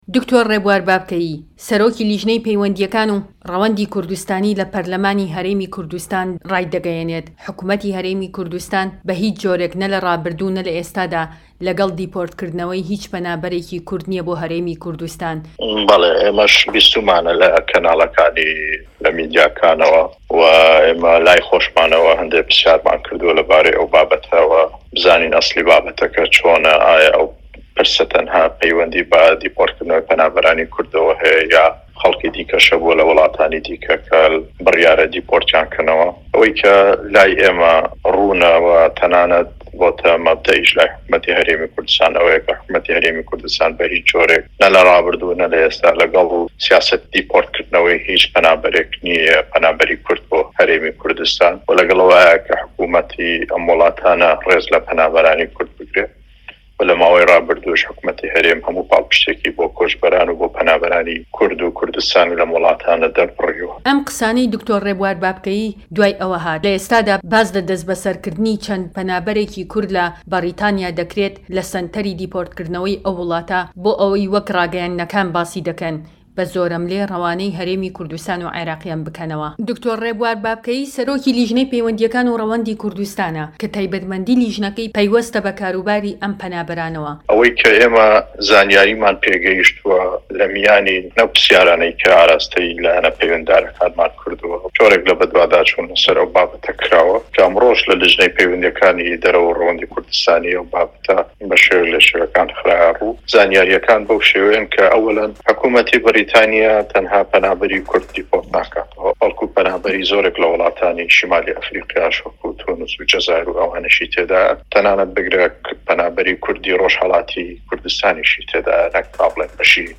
وتووێژ لەگەڵ د. ڕێبوار بابکەیی